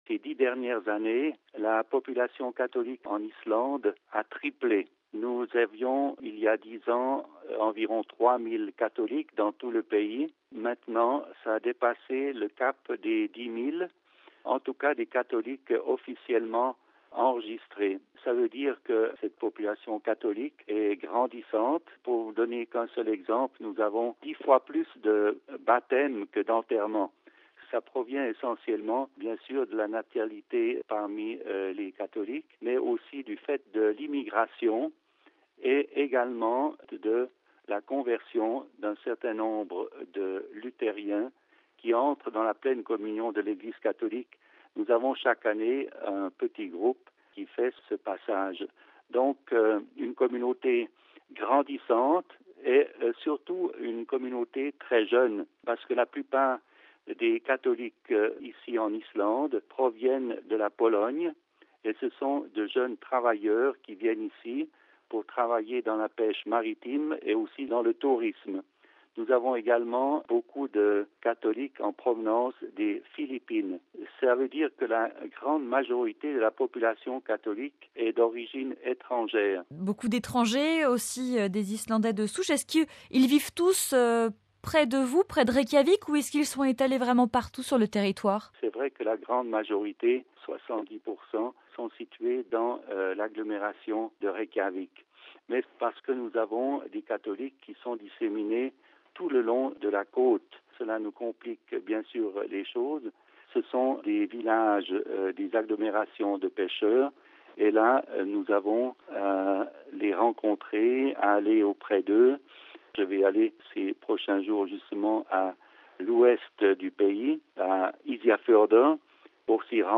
Mgr Pierre Bürcher est l’évêque suisse de Reykjavik depuis 2007. Il nous raconte ce qui a changé sur l’ile depuis son arrivée.